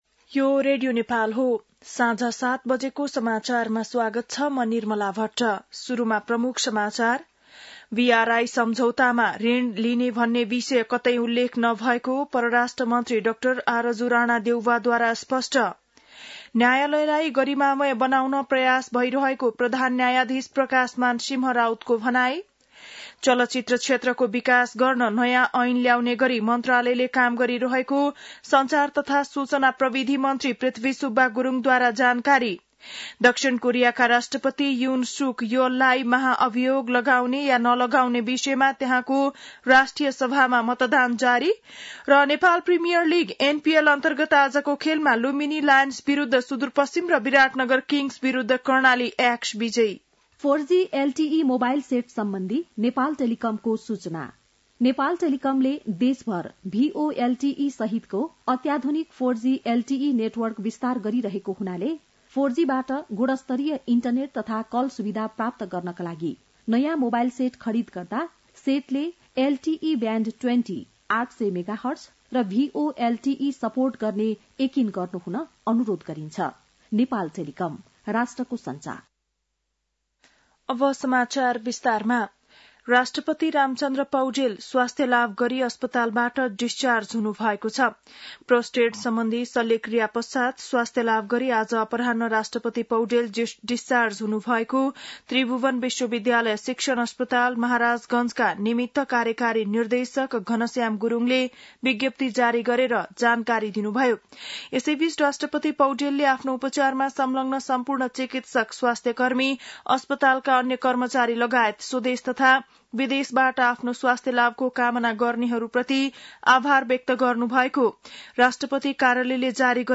बेलुकी ७ बजेको नेपाली समाचार : २३ मंसिर , २०८१
7-PM-Nepali-News-8-22.mp3